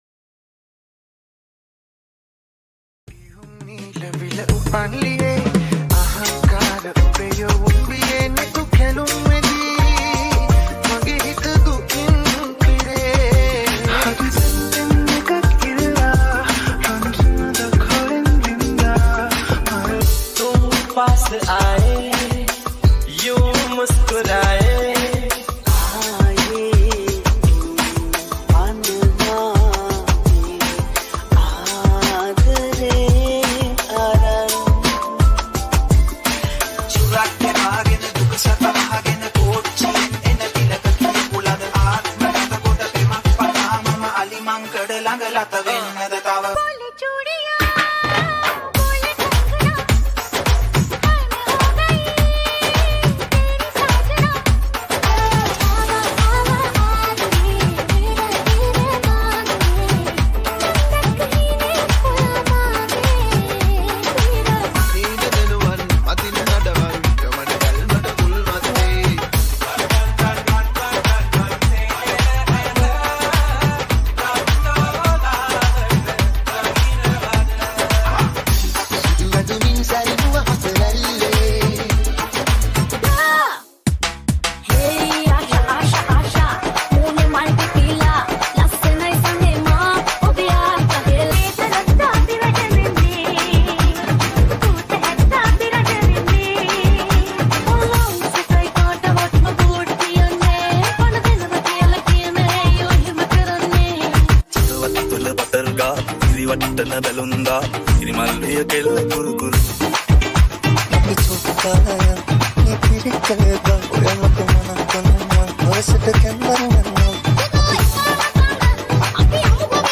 High quality Sri Lankan remix MP3 (3.1).